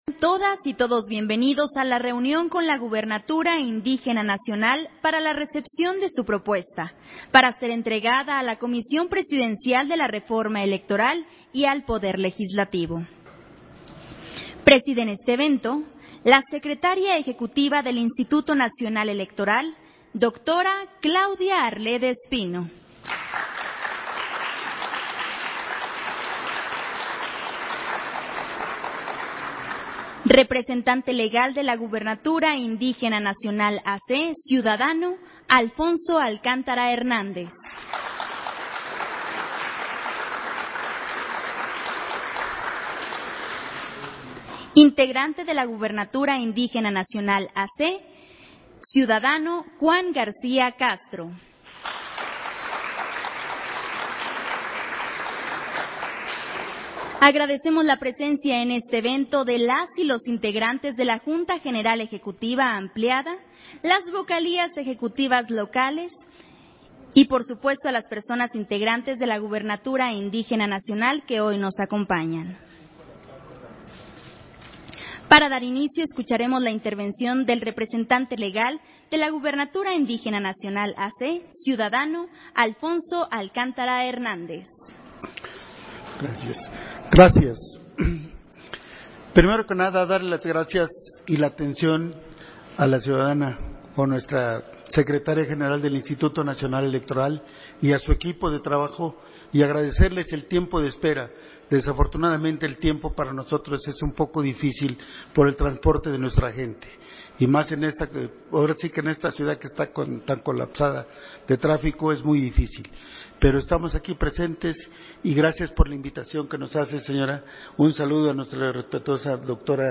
Audio de la reunión con la gubernatura indígena nacional, para la recepción de su propuesta, para ser entregada a la Comisión Presidencial para la Reforma electoral